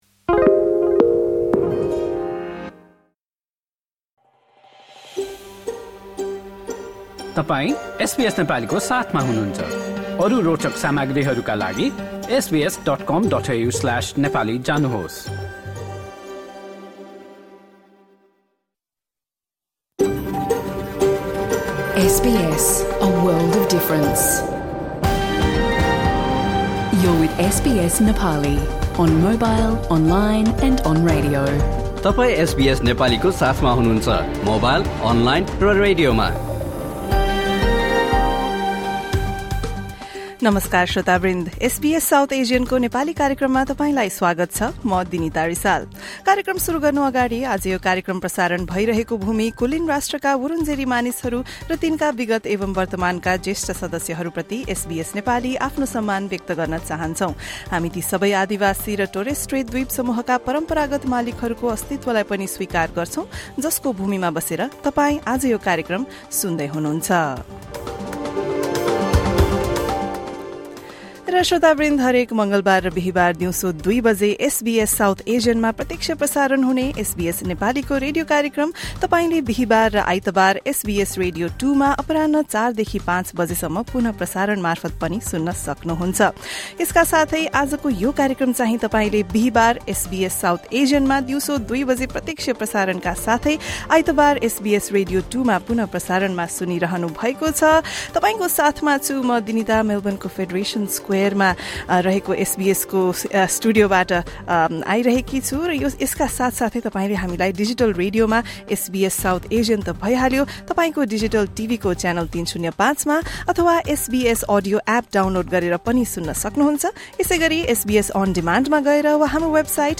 आउँदै गरेको ‘फेस्टिभल अफ लाइट्स’को अवसरमा नेपाली लगायतका एसबीएसका केही भाषा सेवाहरूले क्वीन्सल्यान्डको राजधानी ब्रिसबेनबाट झन्डै १,४०० किलोमिटर उत्तरमा पर्ने क्षेत्रीय शहर केर्न्सबाट आफ्नो रेडियो कार्यक्रम प्रसारण गरेका थिए। बिहिवार, ९ अक्टोबरमा उक्त कार्यक्रममा भाग लिन आएका केही नेपालीभाषीहरूसँग हामीले कुराकानी गरेका थियौँ। रिजनल अस्ट्रेलियाको जीवन, नेपाली समुदाय, तिहार लगायतका विषयमा प्रत्यक्ष रेडियो प्रसारणमा गरिएको कुराकानी पोडकास्टमा सुन्नुहोस्।